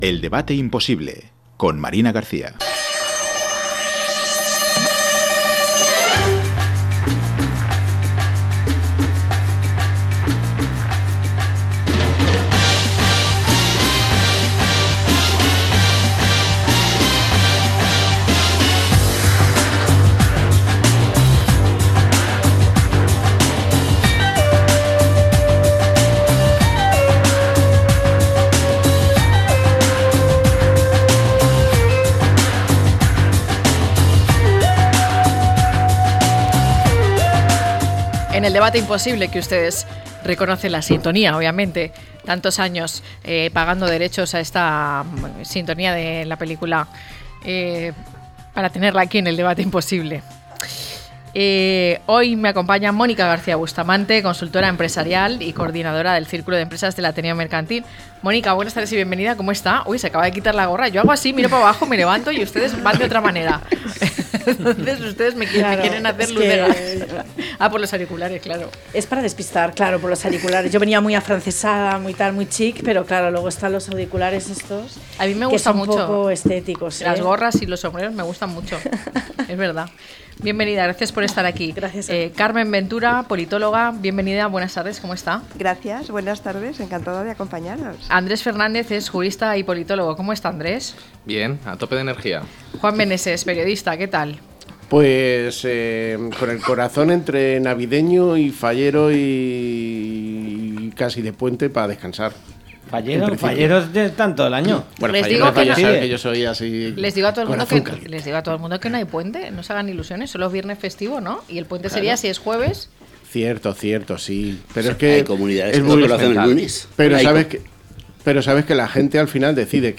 En nuestro debate hablamos sobre las recomendaciones del informe elaborado por expertos del Gobierno para abordar los problemas de salud pública derivados del abuso de pantallas en menores. Desde la idea de «cero pantallas» hasta los seis años, hasta propuestas como teléfonos analógicos para adolescentes y un «botón del pánico» en redes sociales, analizamos los 107 puntos del documento y su impacto en la educación, la salud mental y el desarrollo de los jóvenes. Un debate esencial en un mundo cada vez más digital.